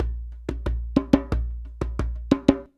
089DJEMB09.wav